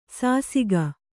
♪ sāsiga